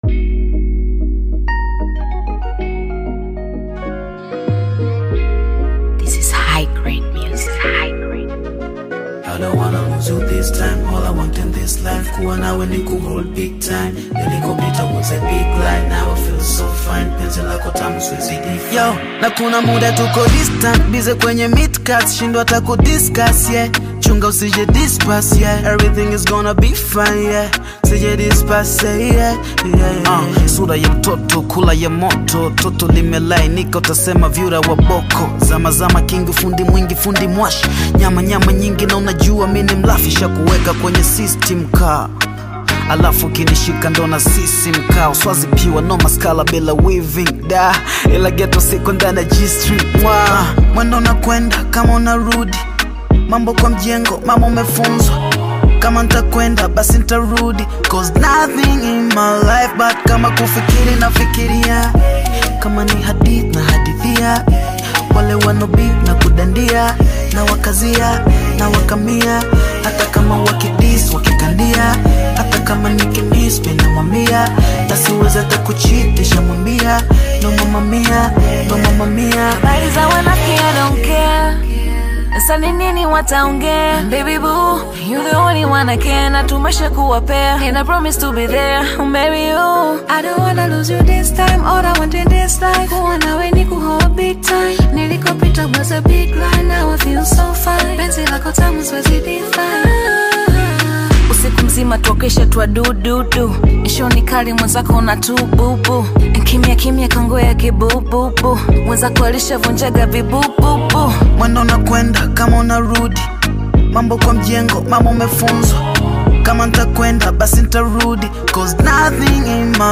bongo flava
Hip-Hop RnB